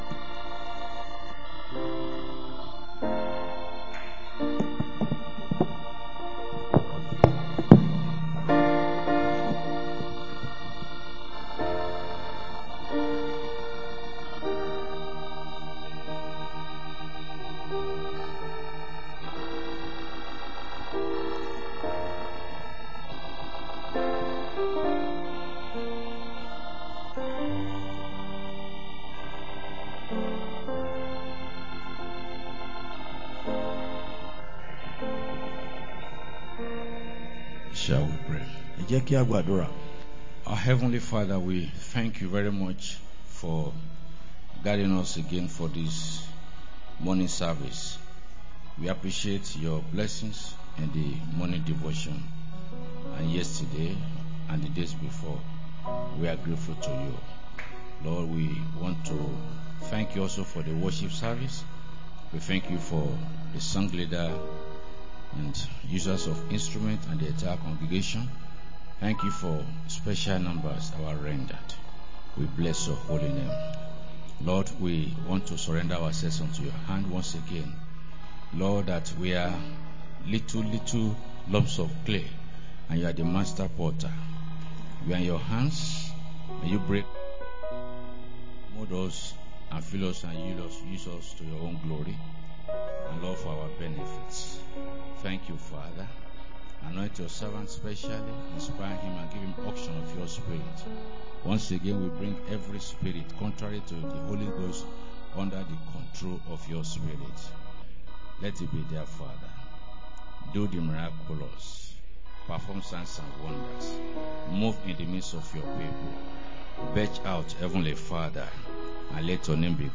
2025 Believers' Convention 06-09-25 Morning Service